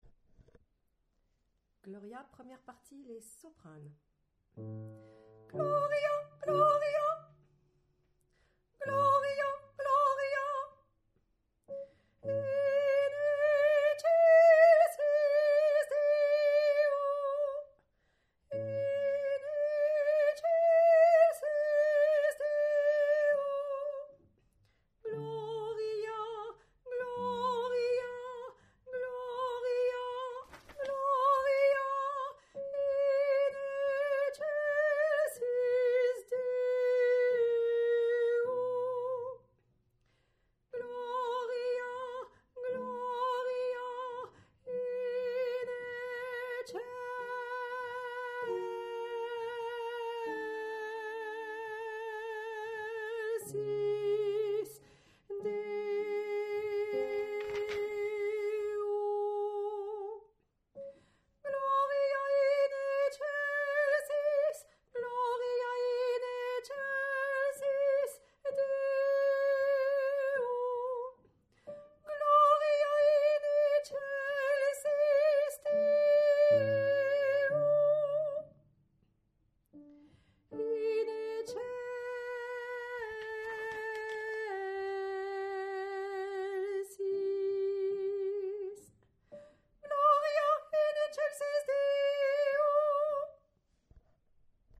gloria1_Soprano.mp3